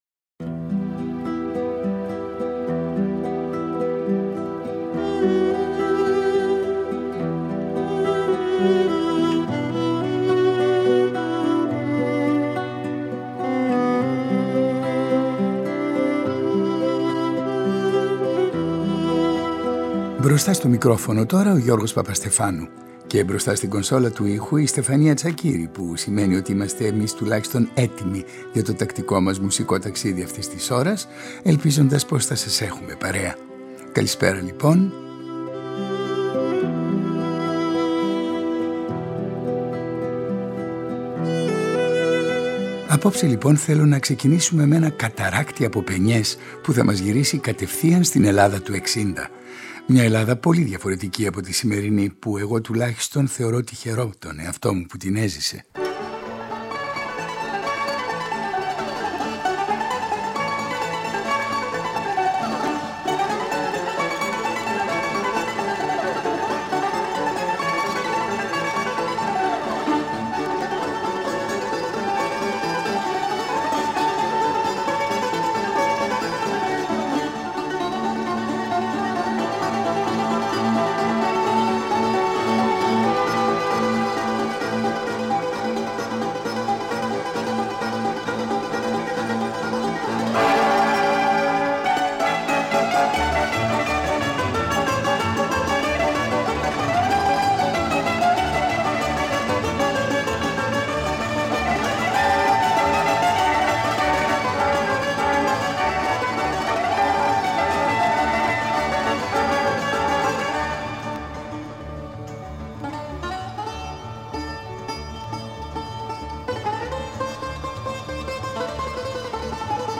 Από τη σειρά εκπομπών του Δεύτερου, «Ραδιονοσταλγία», ακούμε ένα ανεπανάληπτο ραδιοφωνικό οδοιπορικό με την υπογραφή του Γιώργου Παπαστεφάνου για τον ρόλο του Γιώργου Ζαμπέτα στη δικαίωση και καθιέρωση του… καταφρονεμένου μπουζουκιού.